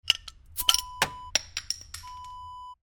Beer bottle open sound effect .wav #3
Description: The sound of opening a beer bottle with a bottle opener and the cap falling on the floor
Properties: 48.000 kHz 24-bit Stereo
A beep sound is embedded in the audio preview file but it is not present in the high resolution downloadable wav file.
beer-bottle-open-preview-3.mp3